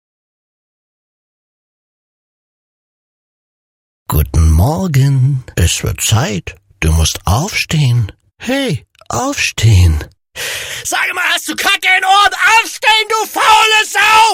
Kategori Alarm